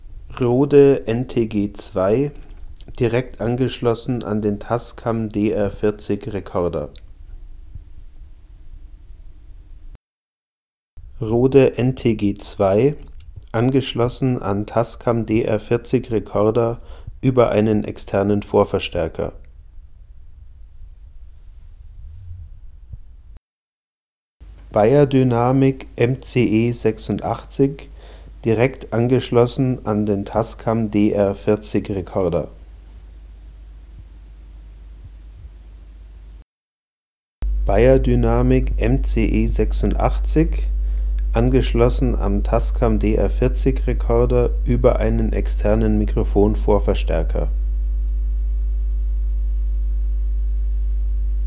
Nun zeigt sich aber, dass das Mikrofon, wenn ich es an einem Vorverstärker (mit Netzteil betrieben) benutze, ein deutliches Netzbrummen einfängt.
Hier Vergleichsaufnahmen zwischen dem Beyerdynamic und dem Rode NTG-2 (genauso hätte ich jedes andere Vergleichsmikrofon nehmen können - am Vorverstärker brummen tut nur das Beyerdynamic):
vergleich_rode_beyerdynamic.mp3